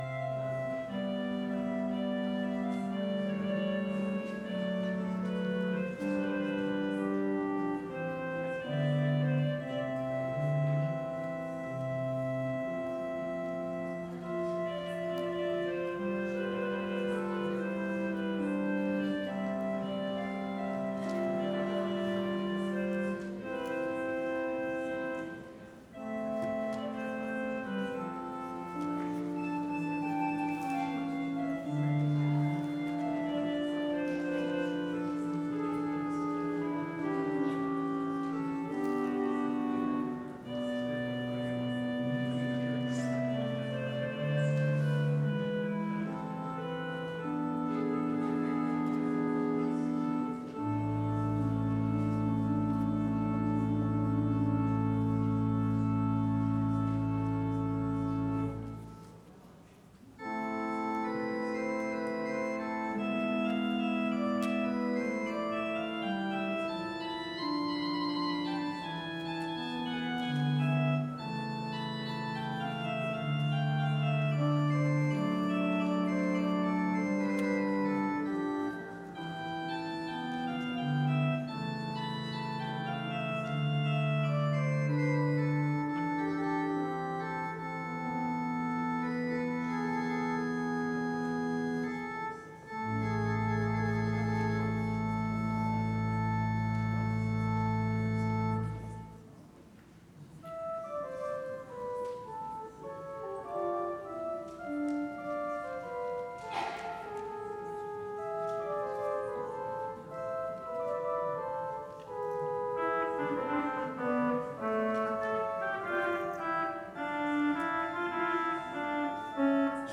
Advent Carol Service Sunday, November 30, 2025